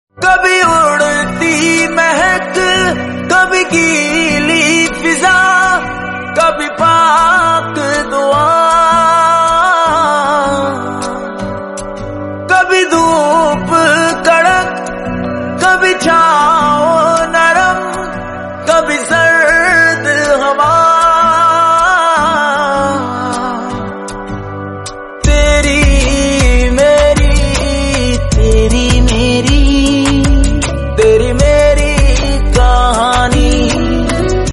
HONDA CITY ASPIRE 1.5 sound effects free download